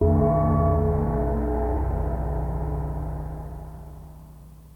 Bell2.ogg